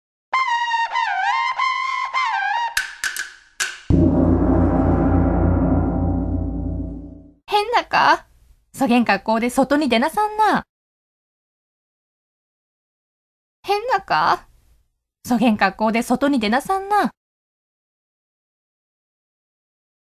• 読み上げ